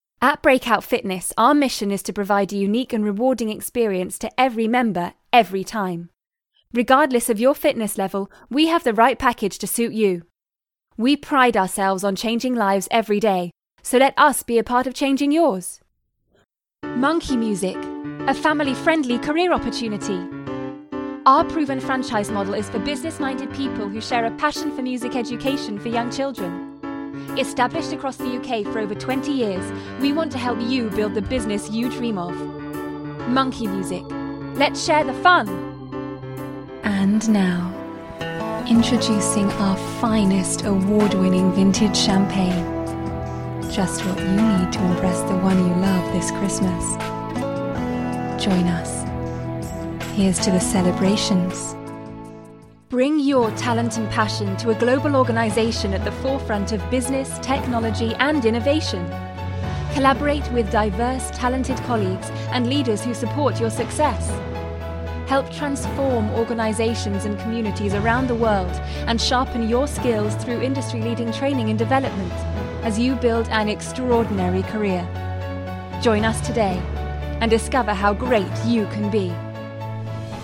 Commercial Reel
• Native Accent: Bristol, RP, Somerset, West Country
• Home Studio